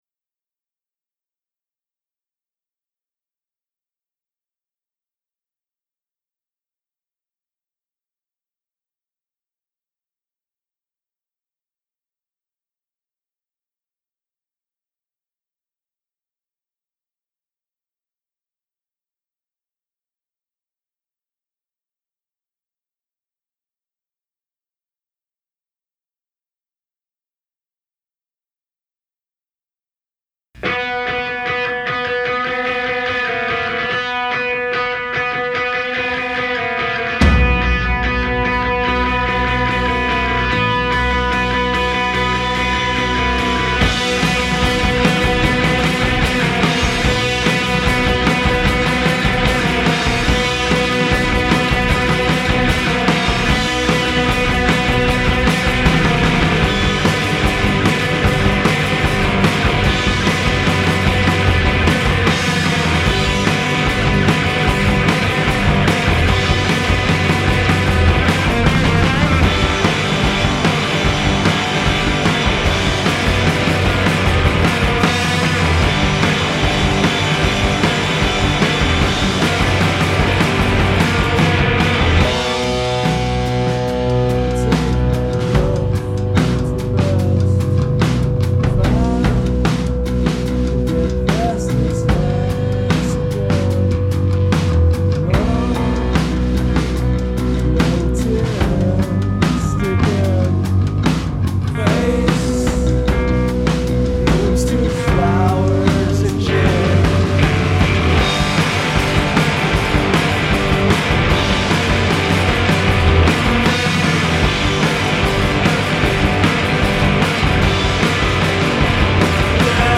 Vocals & Guitar
Bass & Vocals
Drums